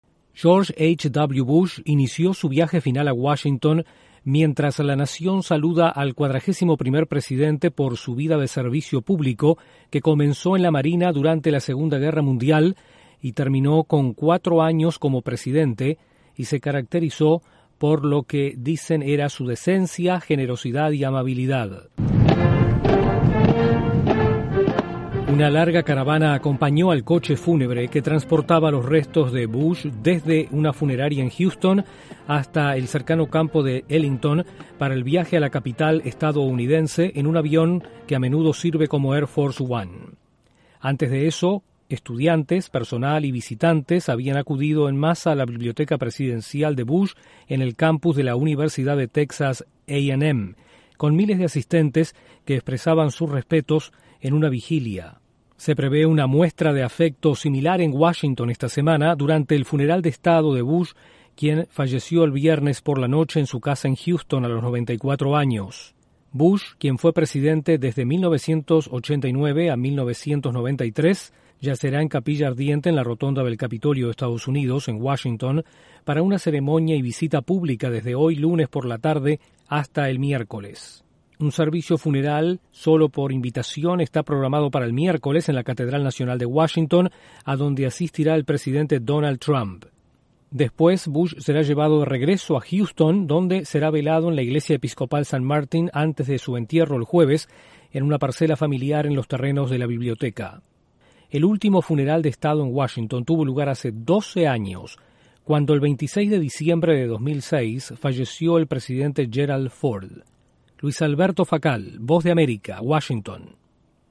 El expresidente de EE.UU. George H. W. Bush es trasladado a Washington, donde los estadounidenses podrán brindar sus respetos. Desde la Voz de América en Washington informa